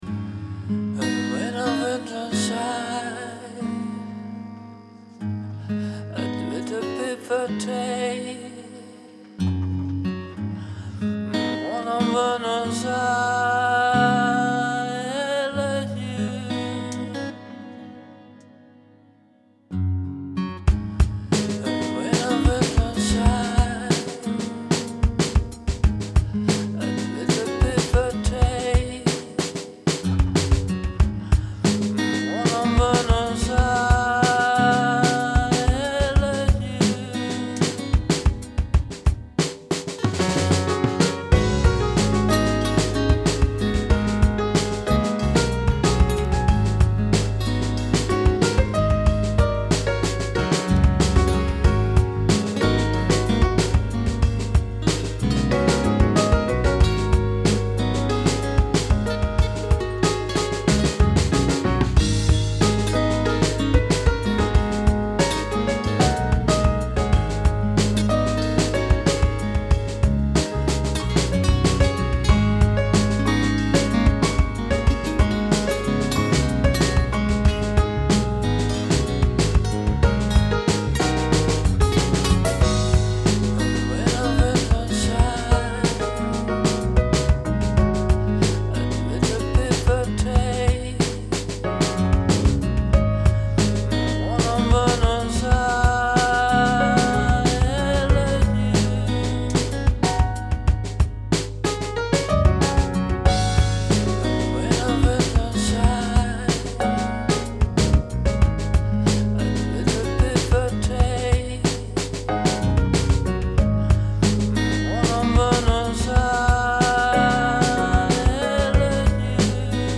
Набросок, что это - джаз, соул, фанк???
Что то получилось за пару дней Вчера записал гитару и слова (птичий язык) Сегодня набросал бас и пианино Пианино немного пластмассовое получилось - что использовать для более реального звука?